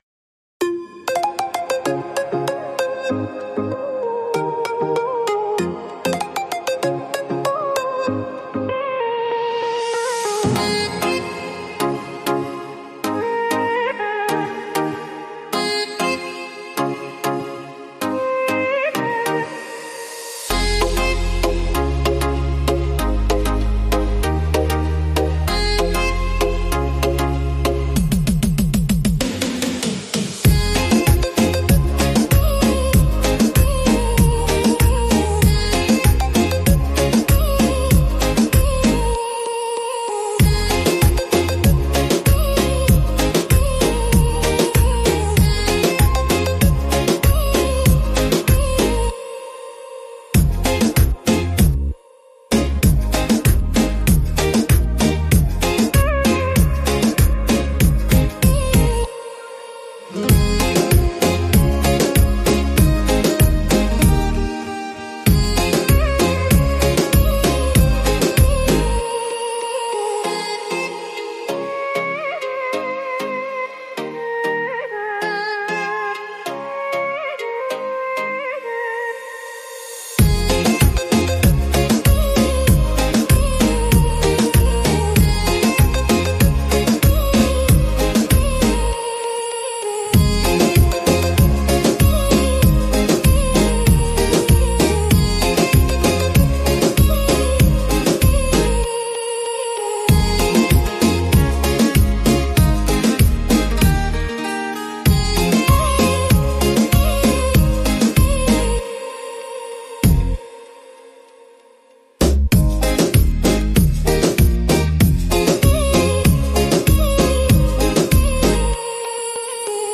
Beat Reggaeton Instrumental
Acapella e Cori Reggaeton Inclusi
G#